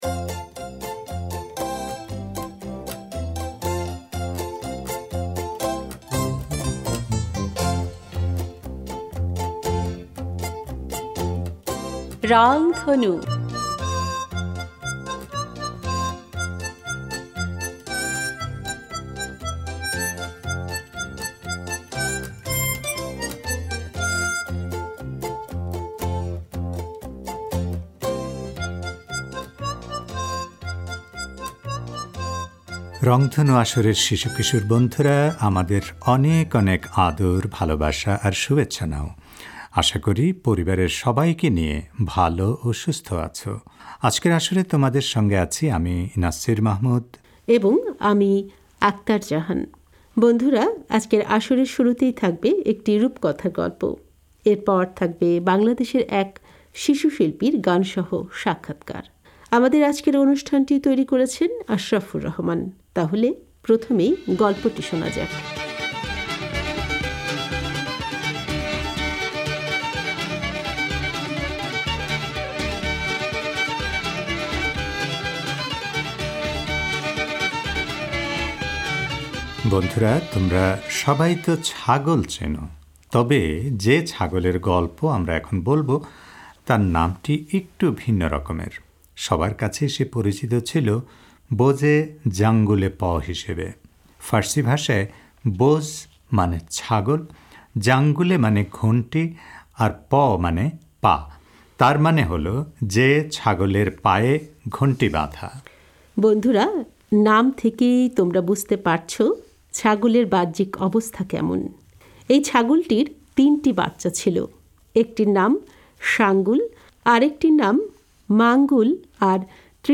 এরপর থাকবে বাংলাদেশের এক শিশুশিল্পীর গানসহ সাক্ষাৎকার।